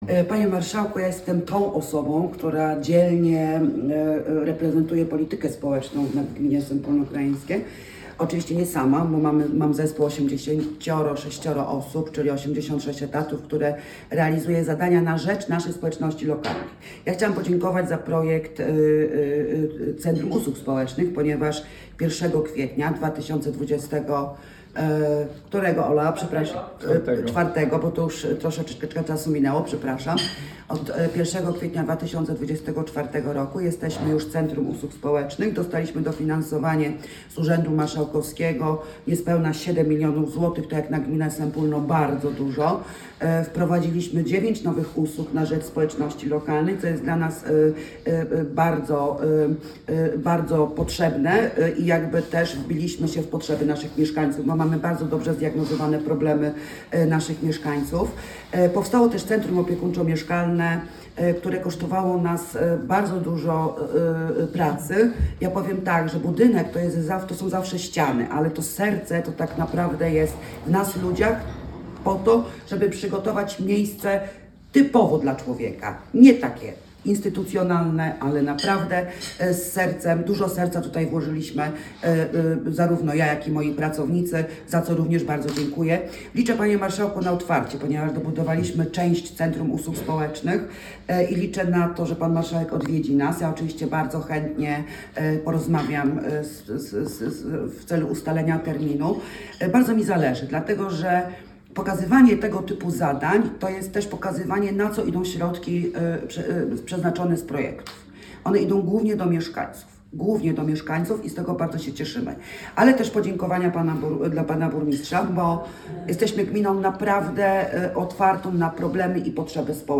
Z wizytą gospodarską w Sępólnie Krajeńskim
Wystąpienie